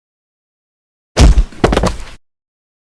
File:Hitsound.wav